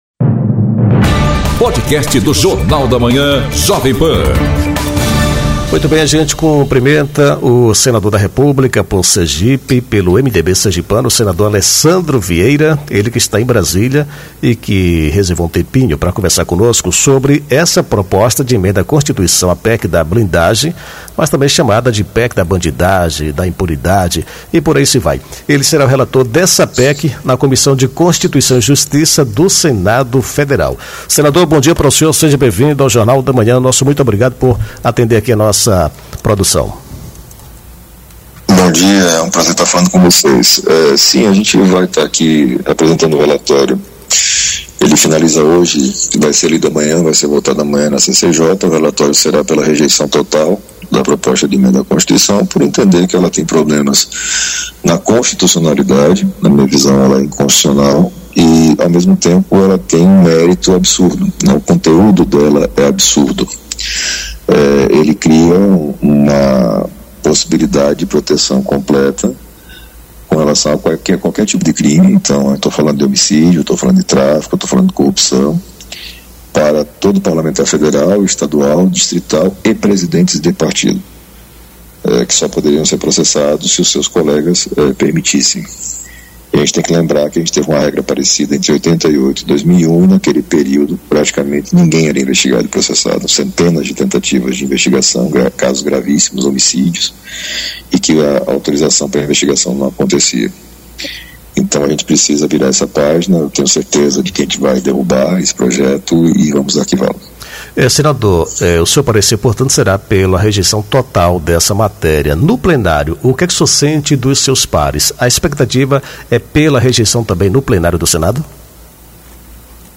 Entrevista com o senador Alessandro Vieira, que fala sobre a PEC da Blindagem e o projeto de lei na Câmara Federal, que define limites de municípios por meio de plebiscito.